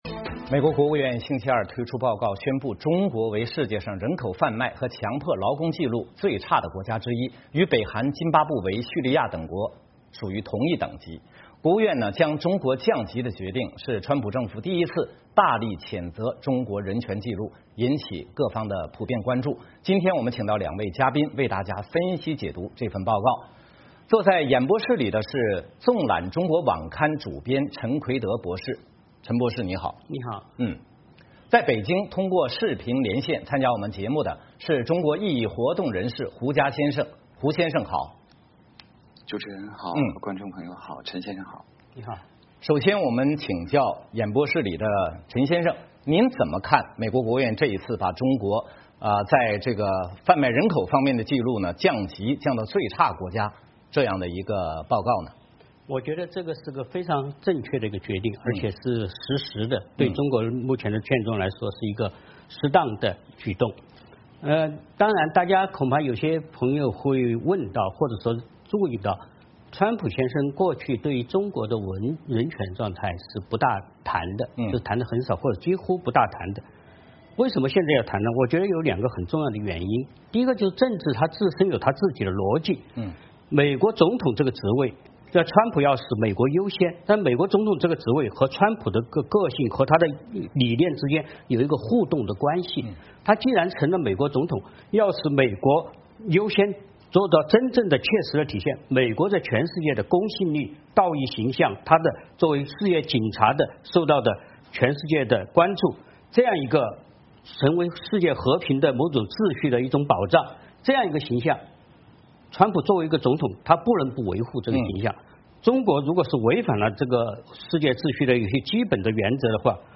时事大家谈是一个自由论坛。